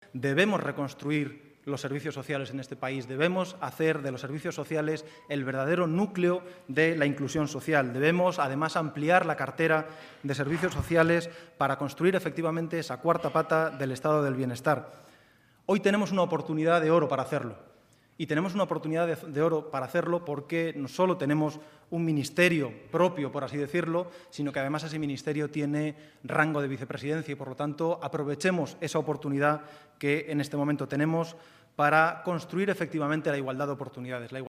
Intervino también Nacho Álvarez quien, como responsable de Derechos Sociales, lamentó el parón que -tras el trabajo del Gobierno Zapatero- sufrieron con la crisis los servicios públicos y cómo la década de recortes que la sucedió se los llevó globalmente por delante.